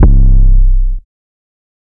Metro Boomin' 808 (C).wav